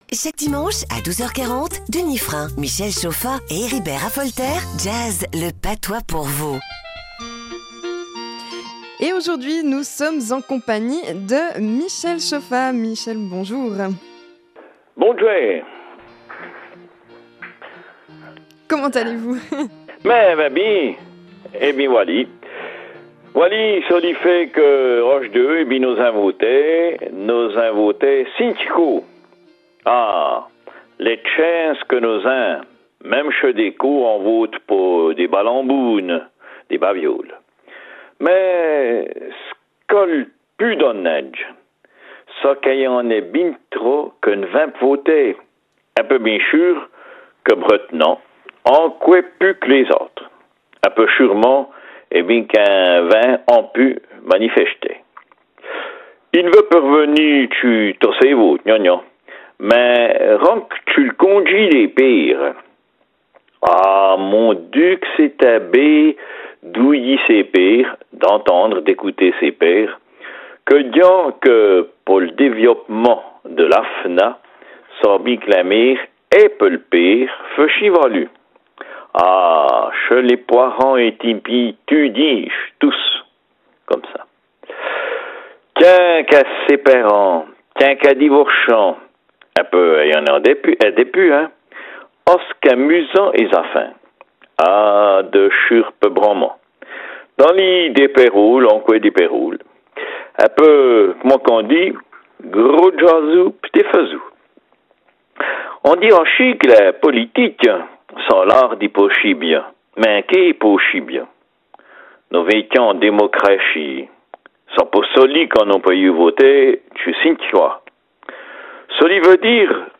RFJ 29 septembre 2020 Radio Fréquence Jura RFJ 29 septembre 2020 Rubrique en patois Auteur